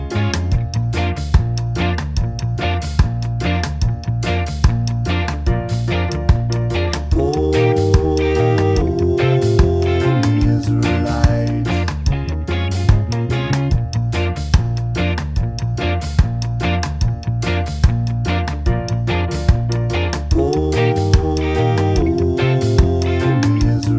no Backing Vocals Reggae 2:56 Buy £1.50